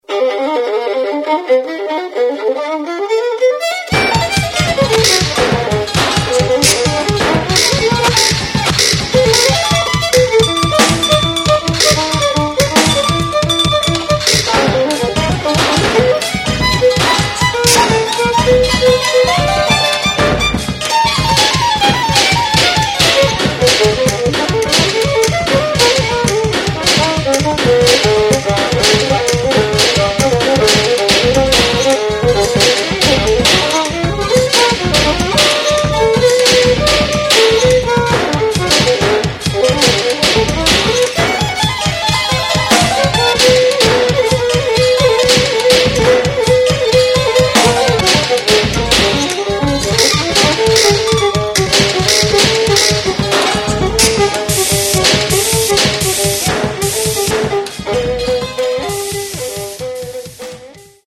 sampling, interactive violin bow, musical instrument factory